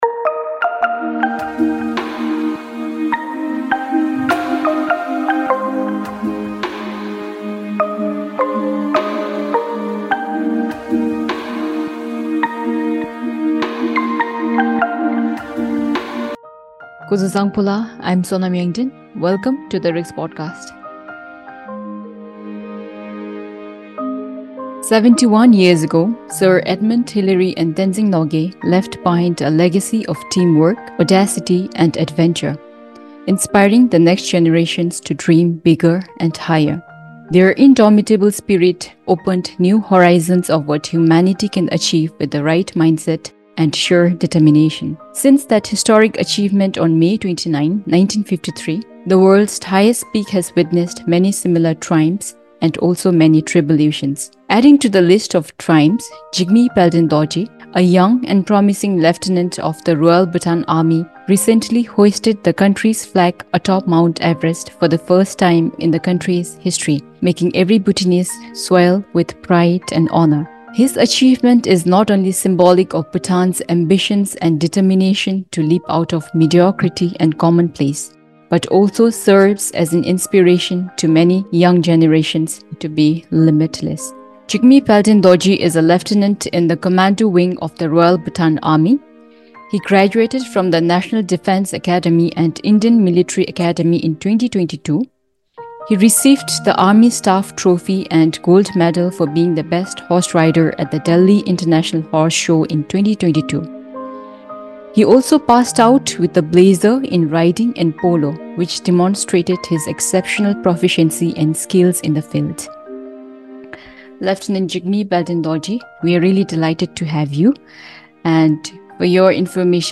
Speaker
In Conversation